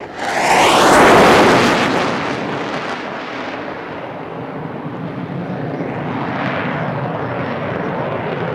F-104 Pass By Tail Exhaust